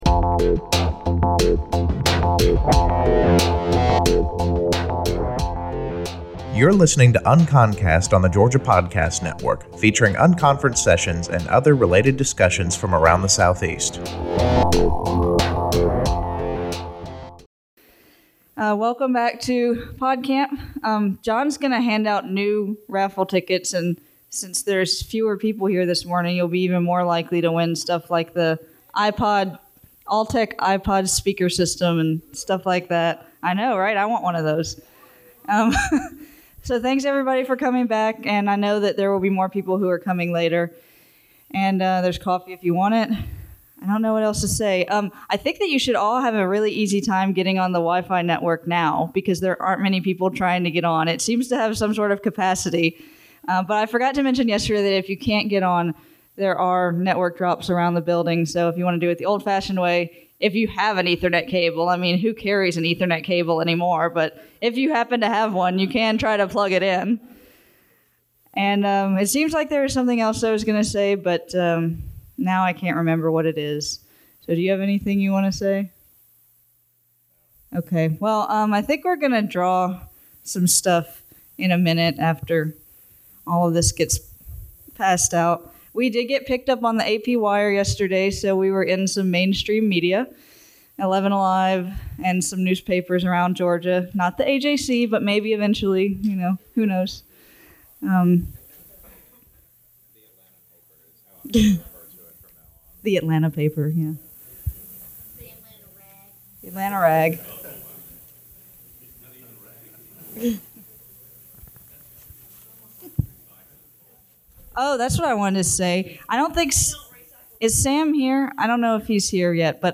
Opening remarks from day 2 of PodCamp Atlanta, a podcasting unconference which took place March 17 and 18 at Emory University in Atlanta.